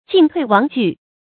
進退亡據 注音： ㄐㄧㄣˋ ㄊㄨㄟˋ ㄨㄤˊ ㄐㄨˋ 讀音讀法： 意思解釋： 同「進退無據」。